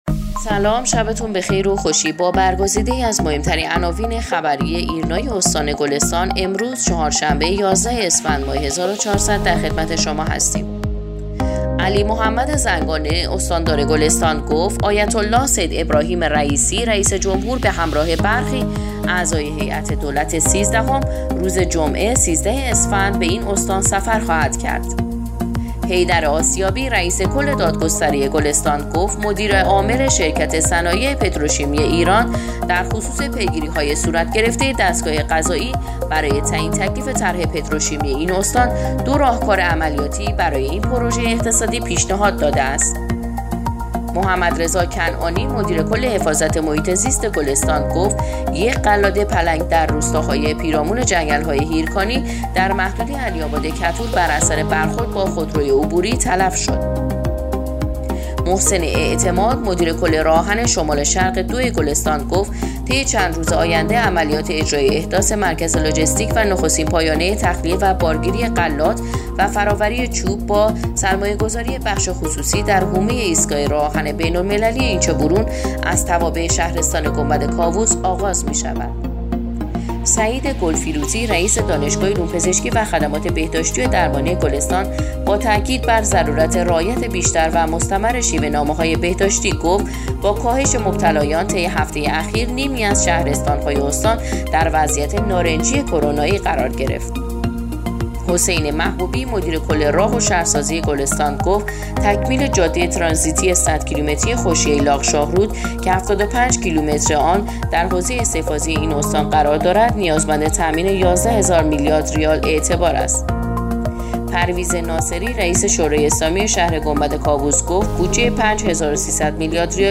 پادکست/ اخبار شبانگاهی یازدهم اسفندماه ایرنا گلستان